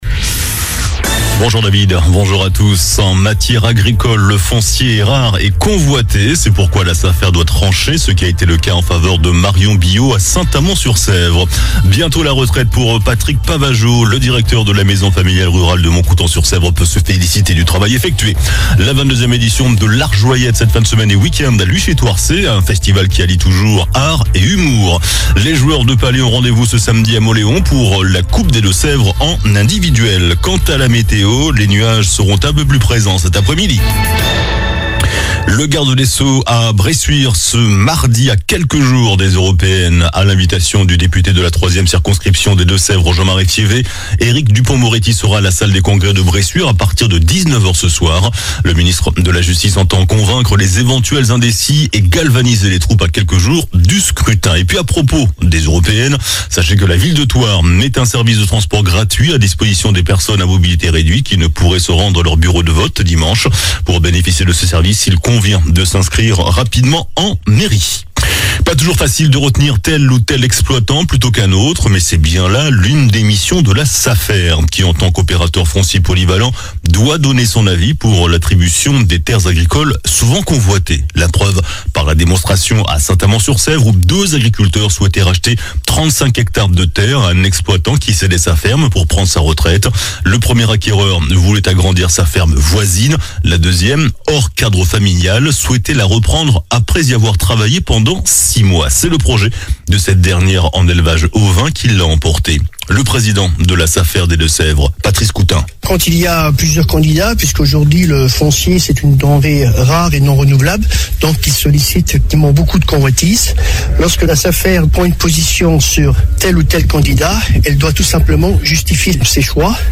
JOURNAL DU MARDI 04 JUIN ( MIDI )